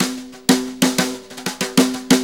Brushes Fill 69-03.wav